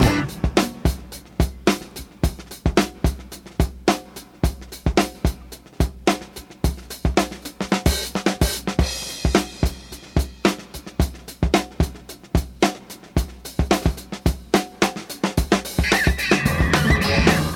• 109 Bpm Modern Breakbeat Sample C Key.wav
Free drum beat - kick tuned to the C note. Loudest frequency: 1470Hz
109-bpm-modern-breakbeat-sample-c-key-nOB.wav